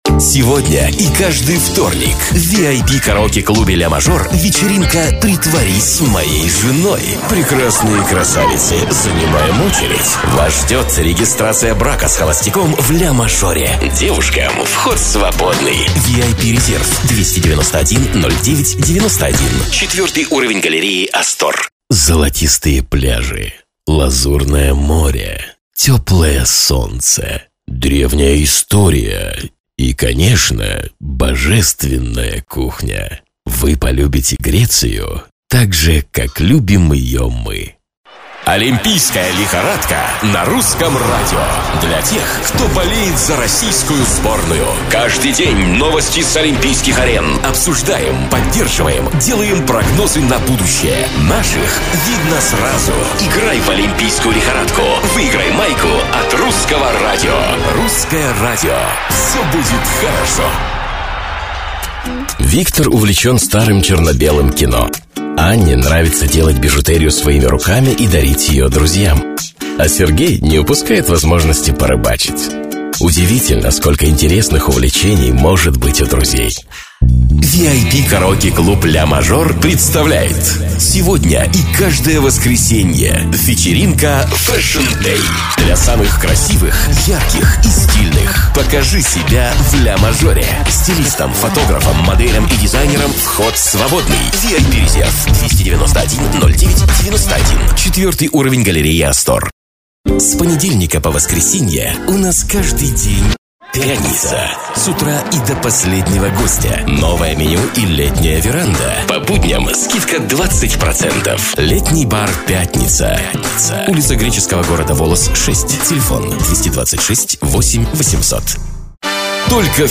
Озвучиваю рекламу, фильмы, мультфильмы, презентации, сказки, игры и т.д. Записываюсь в домашней студии.
Тракт: NEUMANN TLM 102,DBX-376,RME Babyface Pro fs